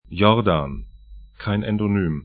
'jɔrdan